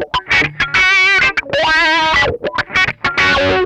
MANIC WAH 3.wav